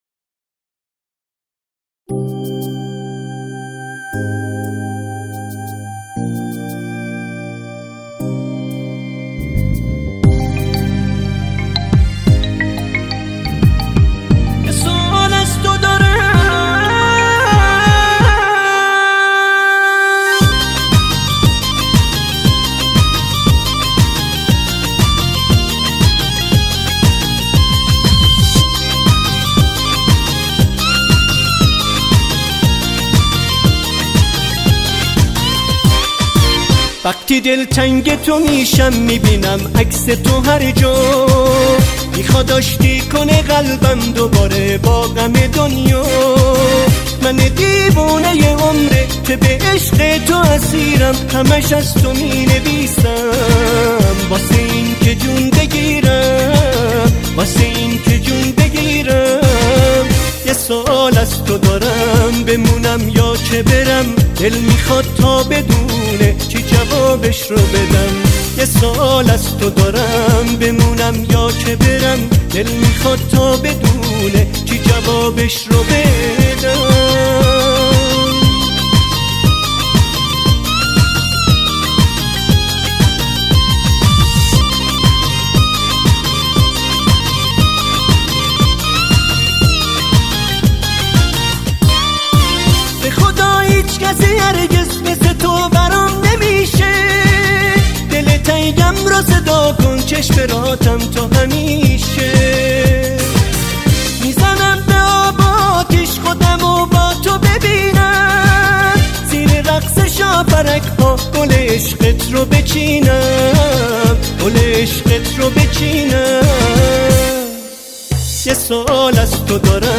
آهنگ نوستالژی ایرانی
آهنگ شاد ایرانی